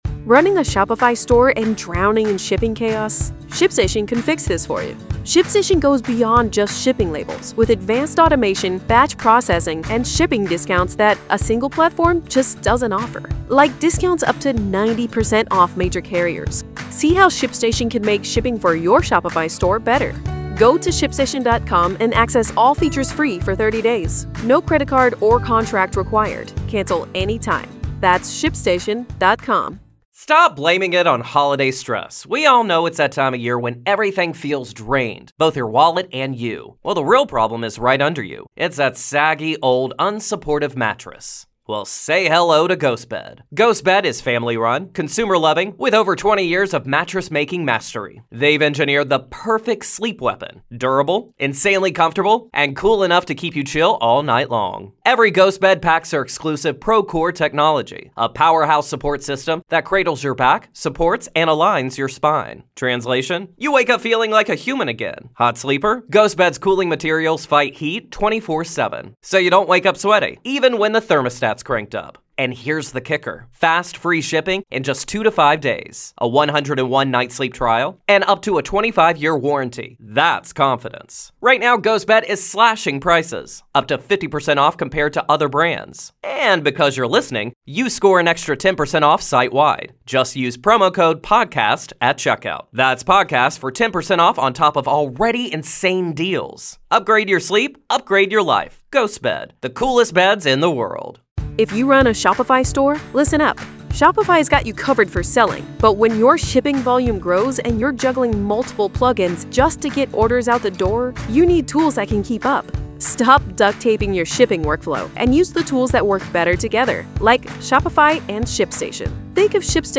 Our exclusive coverage of the Alex Murdaugh murder trial is heating up. Don't miss a beat of the gripping testimony and explosive evidence as the accused faces life-altering charges for the brutal murder of his own family.